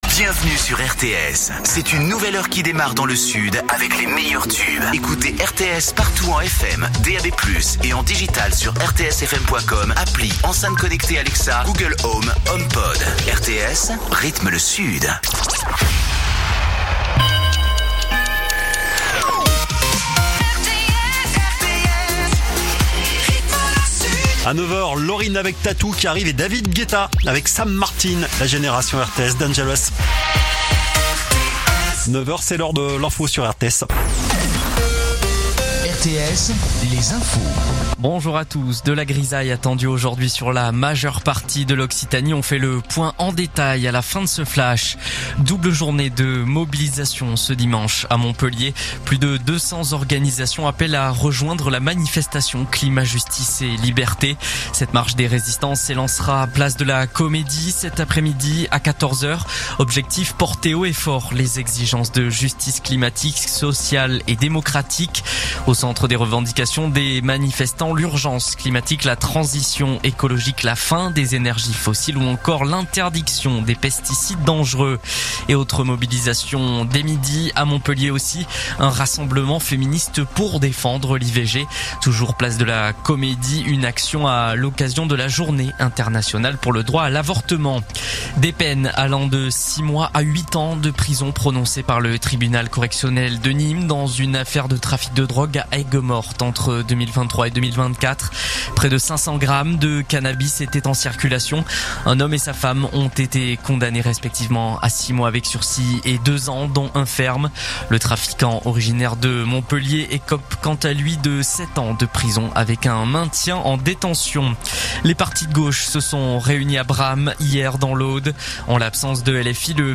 info_narbonne_toulouse_520.mp3